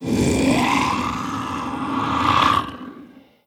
c_agrunt_bat2.wav